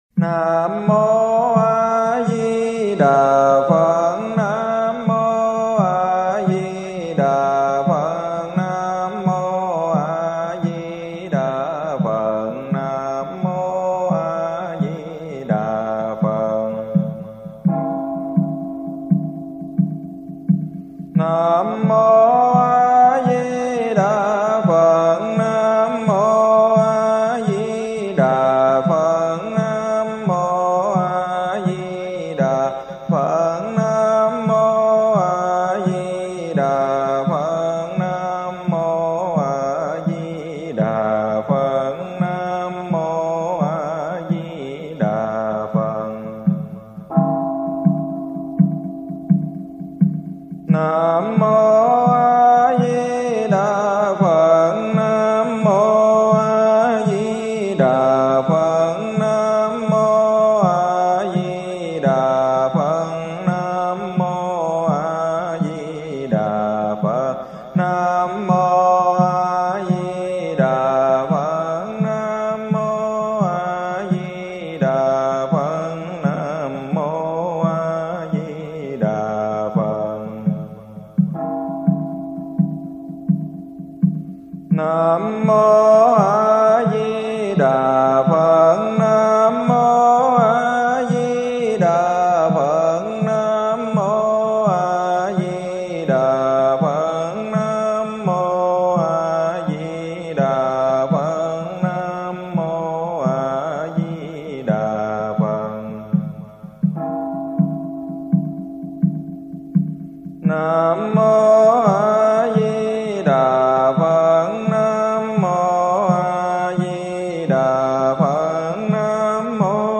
Tiếng Niệm Phật 6 chữ
Thể loại: Tiếng con người
tieng-niem-phat-6-chu-www_tiengdong_com.mp3